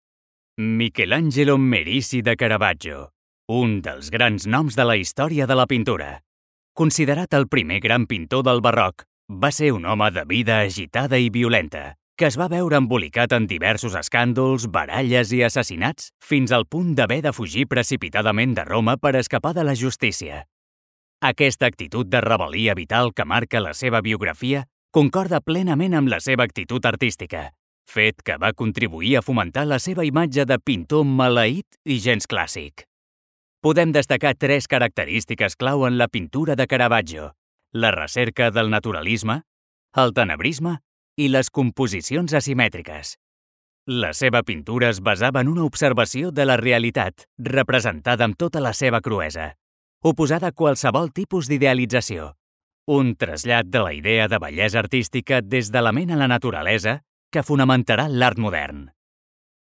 Jeune, Naturelle, Distinctive, Urbaine, Cool
Guide audio